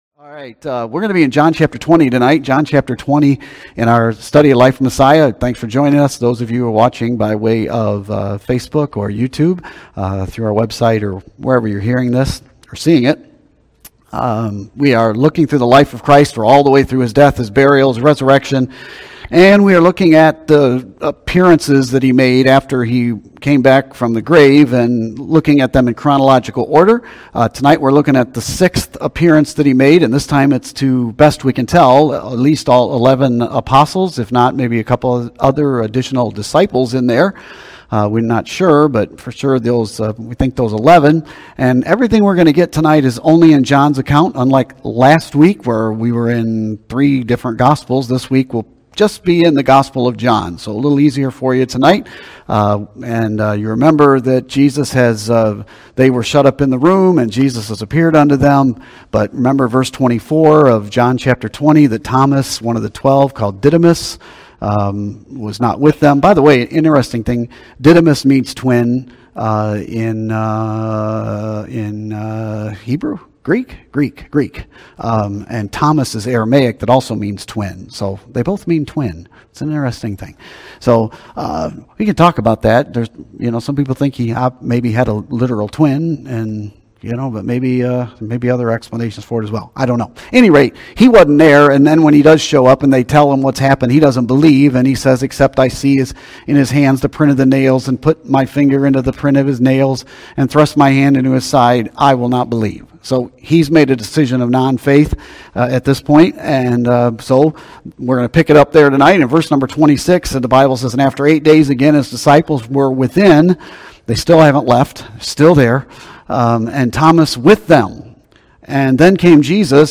Sermons & Teachings | Open Door Baptist Church